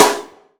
SNARE 113.wav